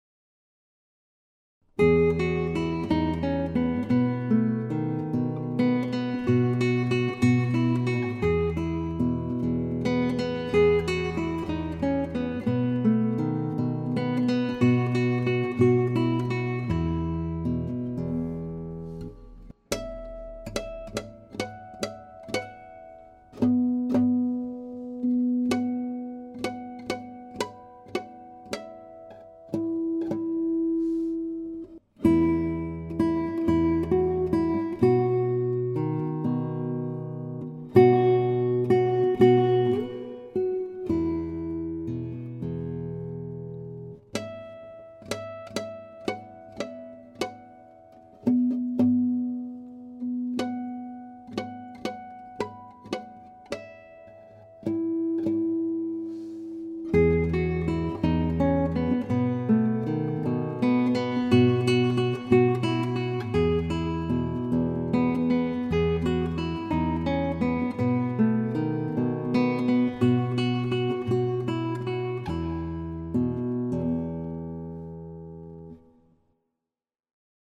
• Solo gitaar
• Thema: Spaans/Flamenco